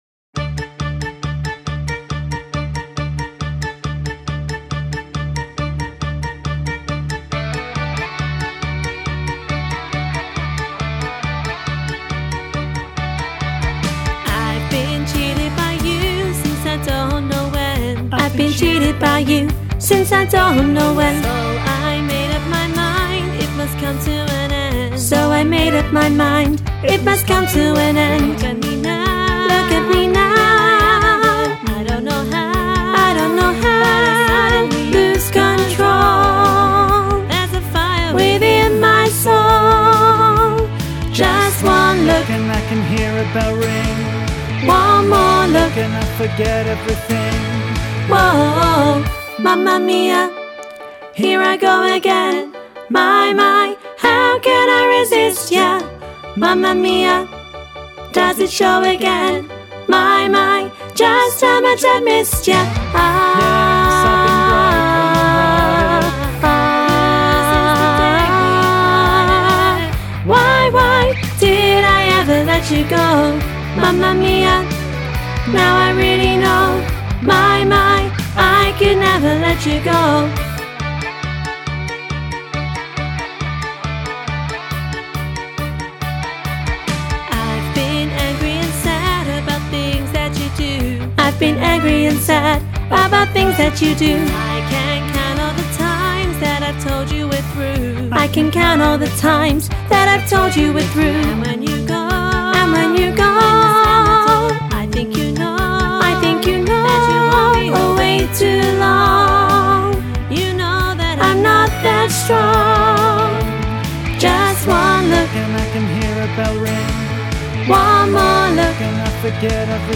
Training Tracks for Mamma Mia
mamma-mia-soprano-half-mix.mp3